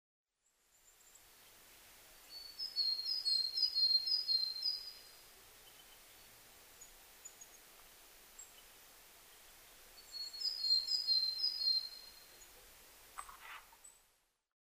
コガラ　Parus montanusシジュウカラ科
日光市稲荷川上流　alt=840m
MPEG Audio Layer3 FILE 128K 　0'15''Rec: EDIROL R-09
Mic: built-in Mic.